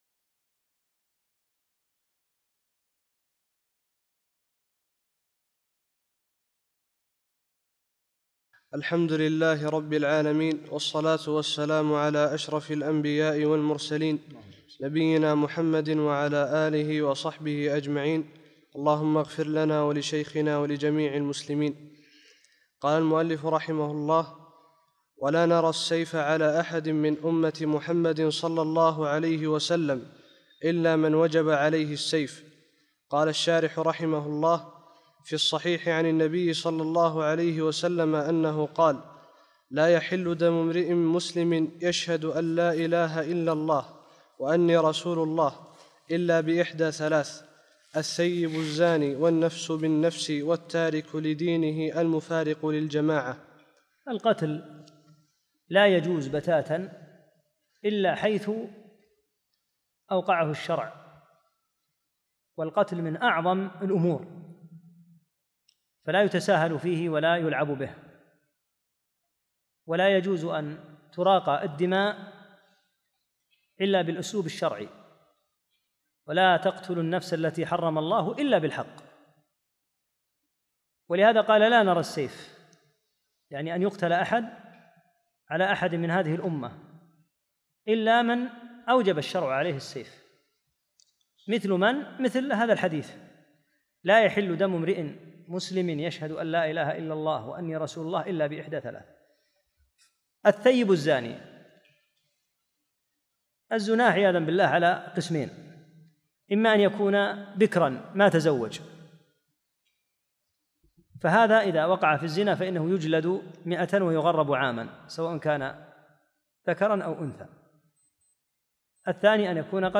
الدرس الخامس عشر